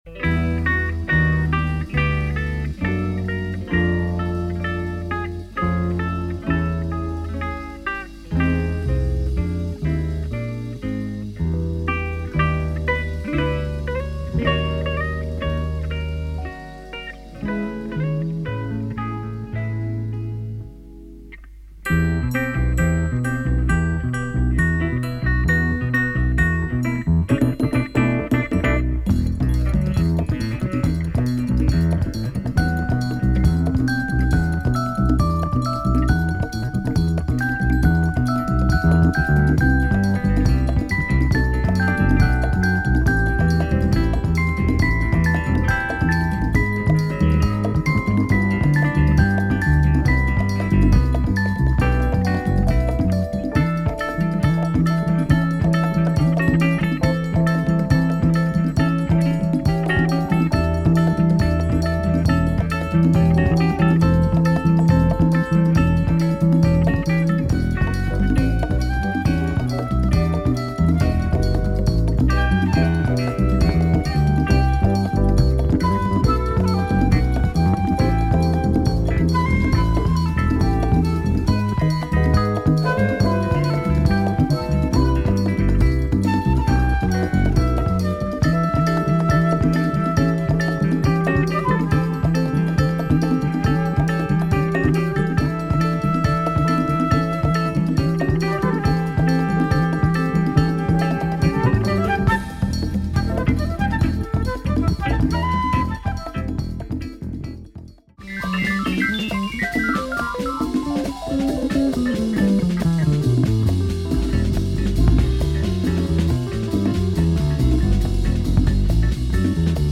Spanish jazz with a beautiful latin flavour.